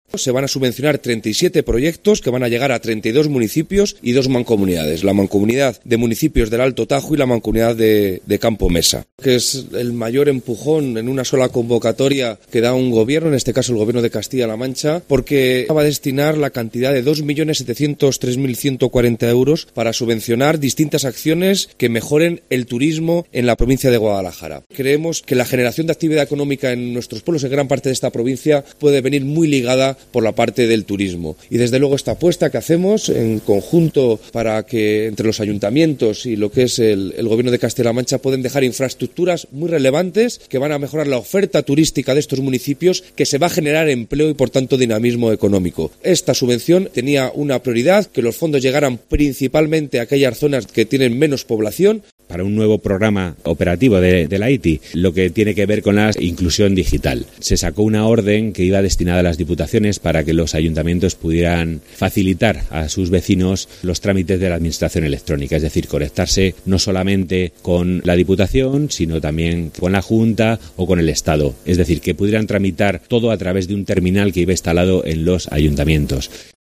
El delegado de la Junta en Guadalajara, Alberto Rojo, ha puesto de manifiesto la importancia de estas ayudas, incluidas en la convocatoria Expresiones de Interés para la asignación de fondos FEDER, en el fortalecimiento del sector turístico de la provincia.
El director general de Coordinación y Planificación, Eusebio Robles, se ha referido a las subvenciones para la inclusión digital de los municipios.